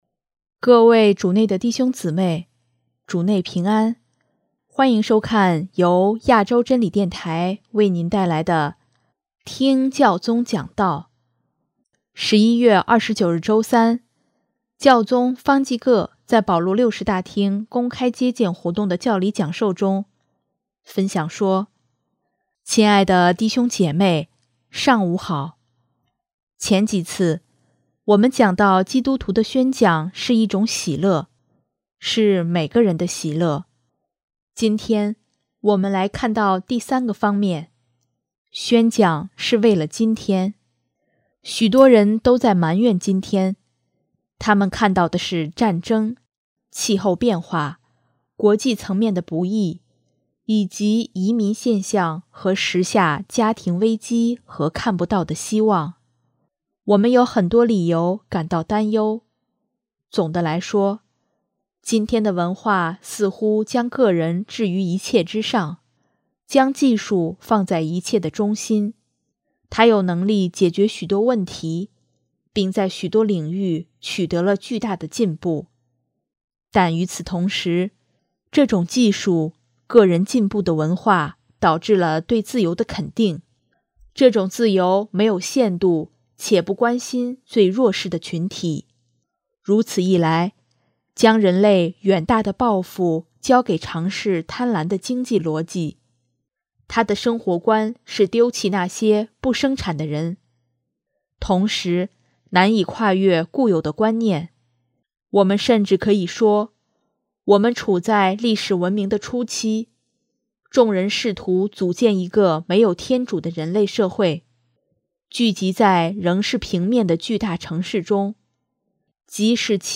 11月29日周三，教宗方济各在保禄六世大厅公开接见活动的教理讲授中，分享说：